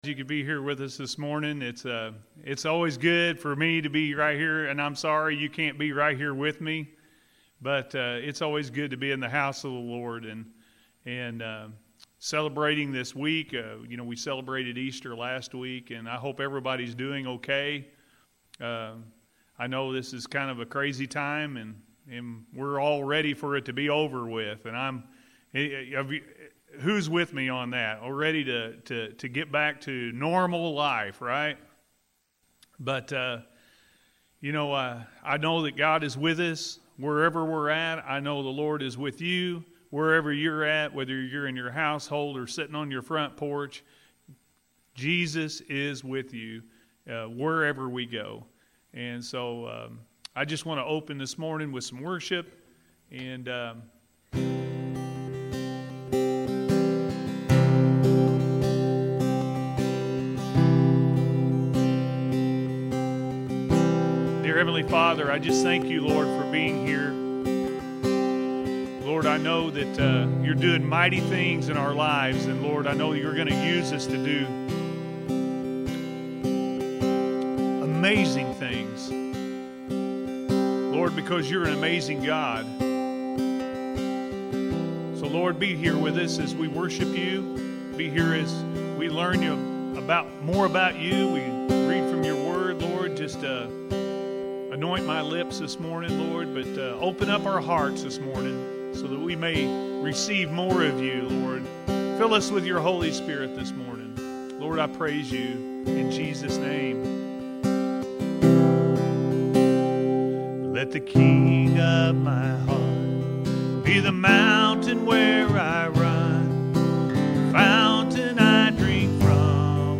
The Emmaus Walk-A.M. Service